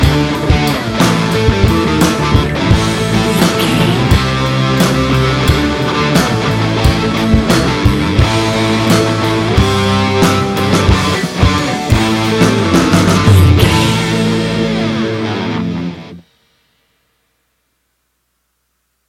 Ionian/Major
A♭
hard rock
heavy rock
distortion
instrumentals